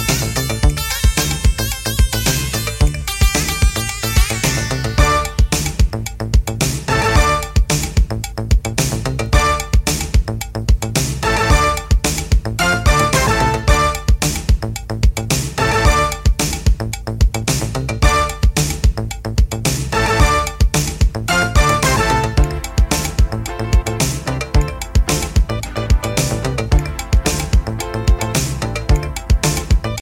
> NU DISCO・BALEARIC・NU JAZZ・CROSSOVER・REGGAE
ジャンル(スタイル) DISCO / SOUL / HOUSE